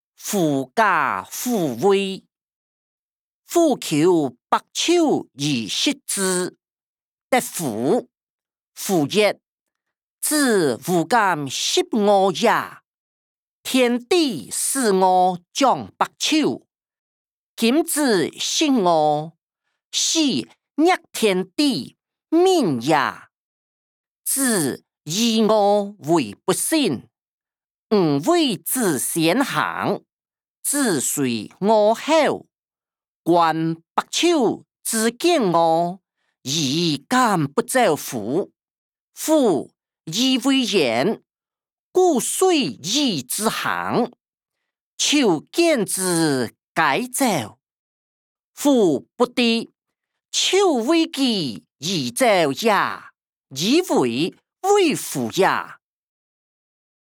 歷代散文-狐假虎威音檔(大埔腔)